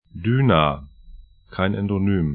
Düna 'dy:na Daugava 'daugava lv Fluss / stream 57°03'N, 24°02'E